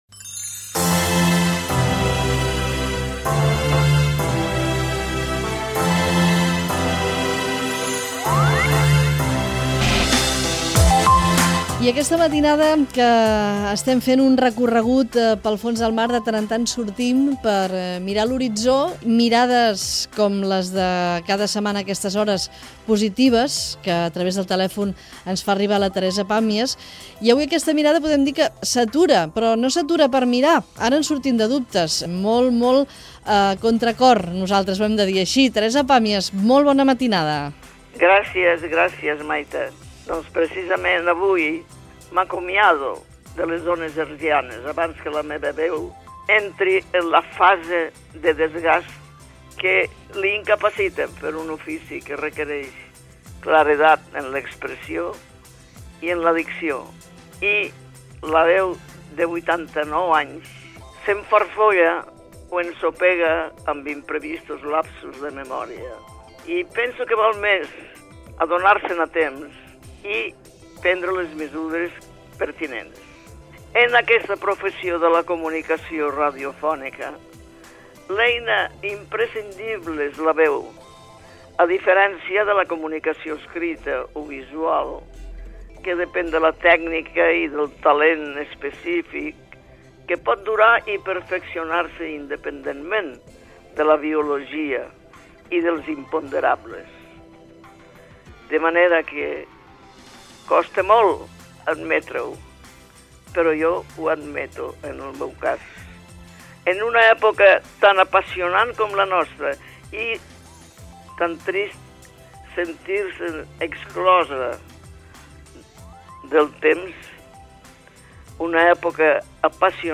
Espai "La mirada positiva". La col·laboradora Teresa Pàmies, als 89 anys, s'acomiada de la ràdio. Escrit sobre la veu, els condicionats de la vellesa i les emissores internacionals i nacionals on va treballar